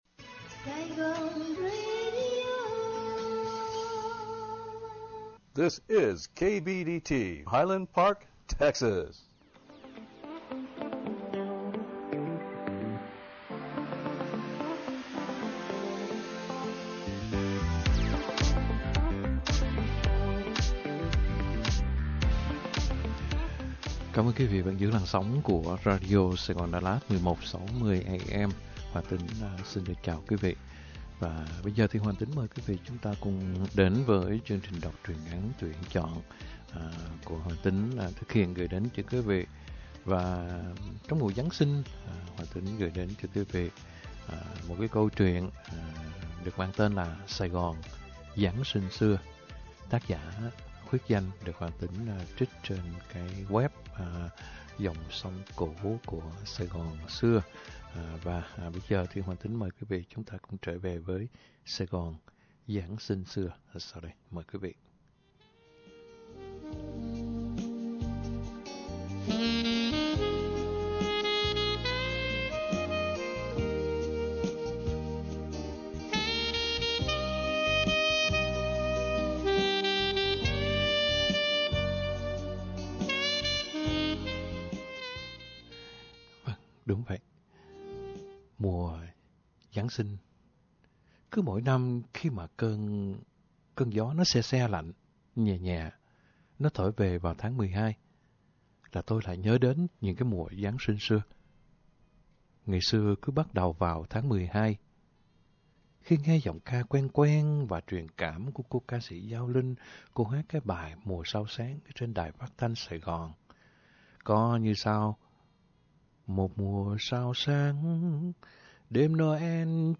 Đọc Truyện Ngắn = Sài Gòn Giáng Sinh Xưa - 12/20/2022 . | Radio Saigon Dallas - KBDT 1160 AM